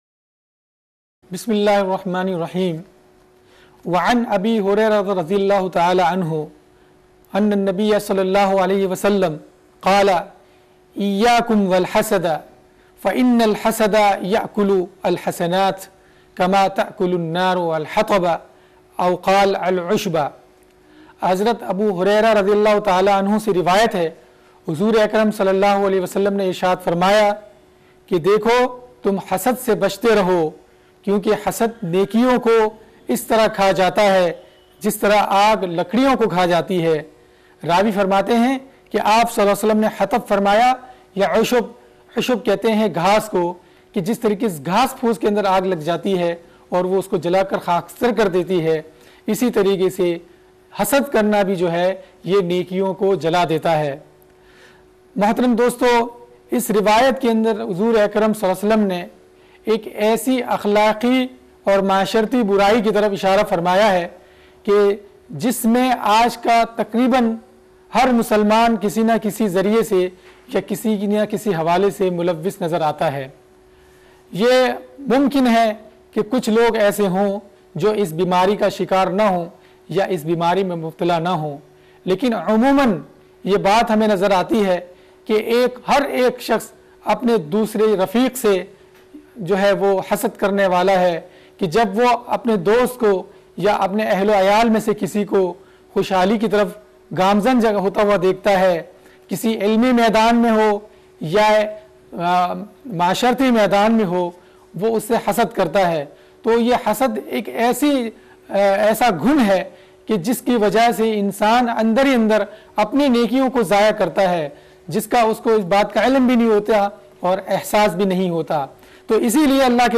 Lectures - Listen/Download